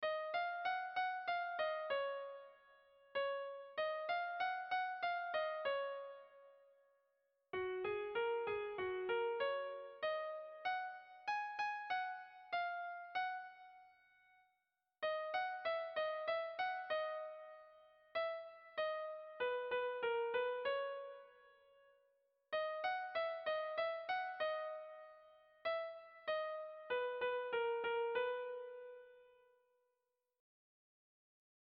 Erlijiozkoa
Zortziko txikia (hg) / Lau puntuko txikia (ip)